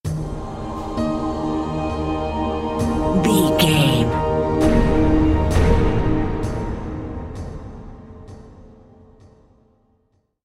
Aeolian/Minor
piano
synthesiser
drums
harp
ominous
dark
suspense
haunting
creepy